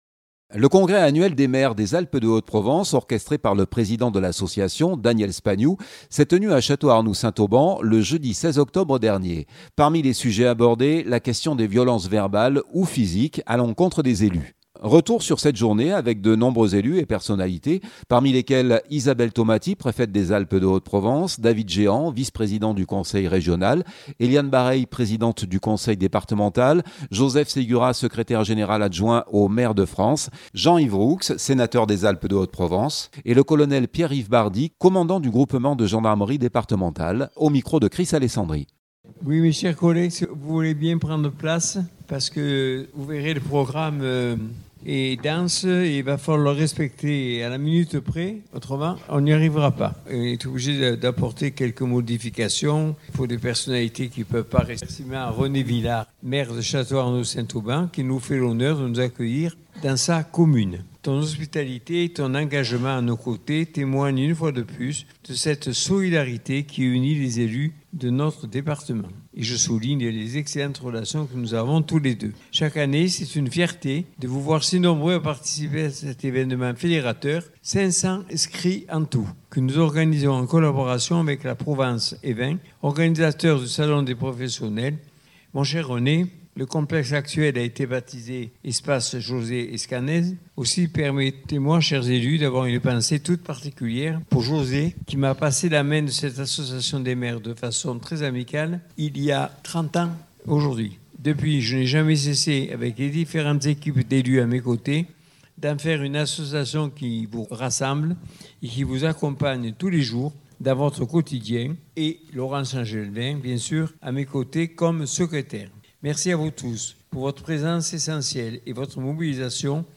2025-10-21 - Château-Arnoux-Congrès des Maire des AHP-.mp3 (35.65 Mo) Le congrès annuel des maires des Alpes-de-Haute-Provence, orchestré par le président de l’association: Daniel Spagnou, s’est tenu à Château-Arnoux-Saint-Auban le jeudi 16 octobre dernier. Parmi les sujets abordés : la question des violences verbales ou physiques à l’encontre des élus.